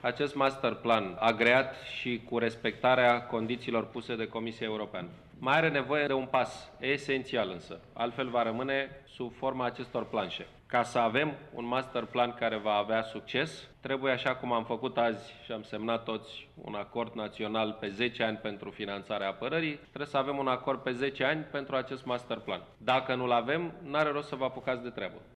Astăzi, la prezentarea priorităţilor de infrastructură rutieră pentru perioada 2015-2016, premierul Victor Ponta a explicat că fără acest acord politic, Planul General de Transport nu ar avea şanse de reuşită, întrucât constructorii au nevoie de garanţii de finanţare pe termen lung: